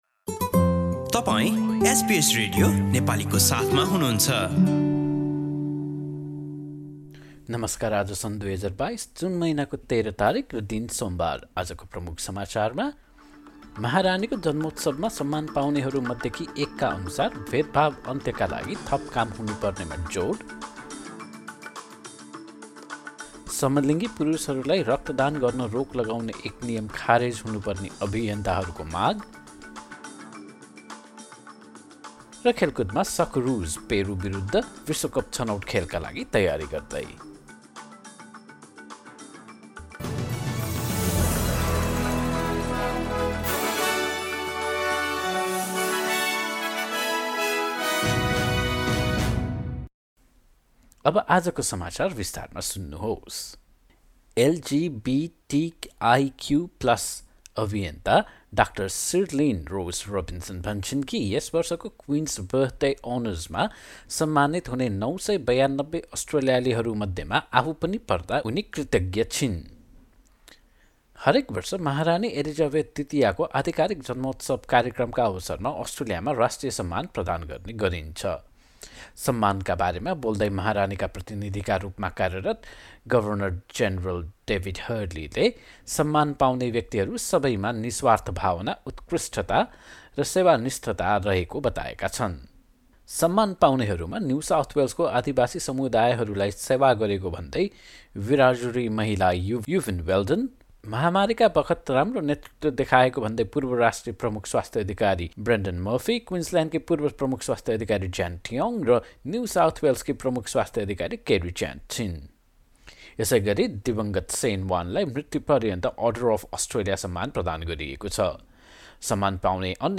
एसबीएस नेपाली अस्ट्रेलिया समाचार: सोमवार १३ जुन २०२२